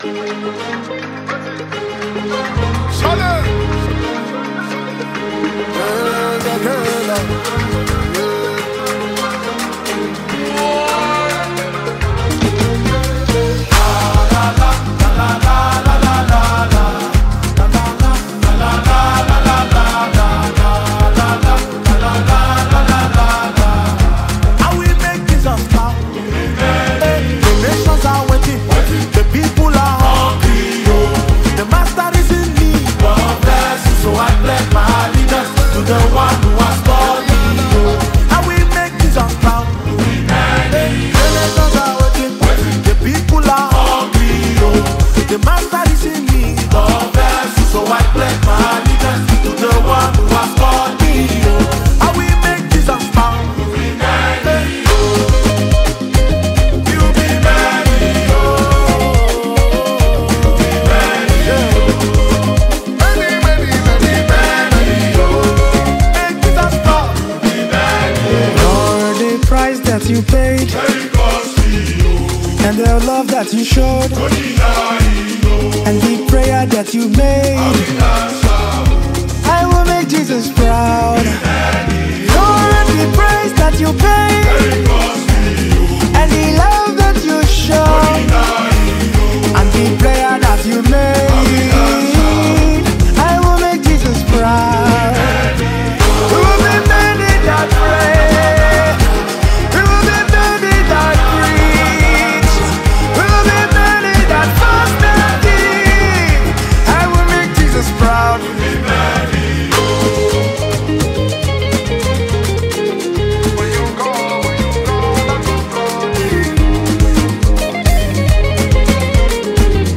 Nigeria talented gospel music singer